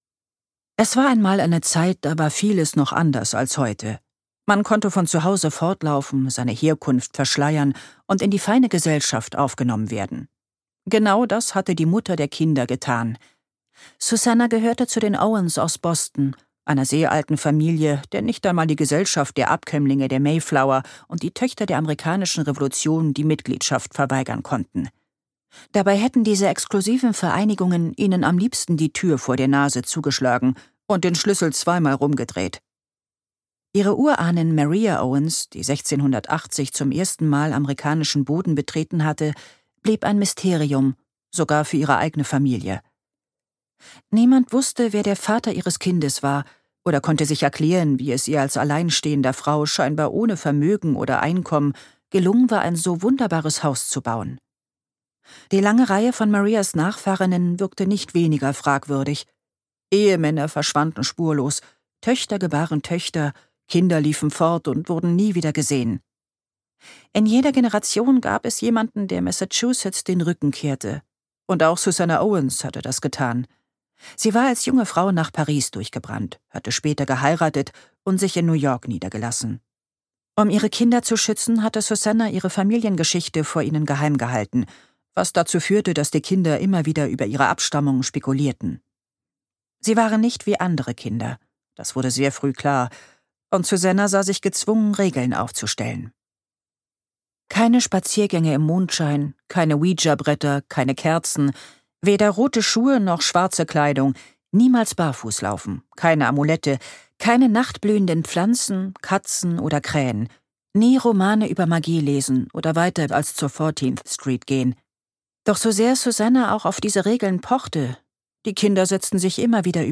Eine zauberhafte Familie - Alice Hoffman | argon hörbuch
Gekürzt Autorisierte, d.h. von Autor:innen und / oder Verlagen freigegebene, bearbeitete Fassung.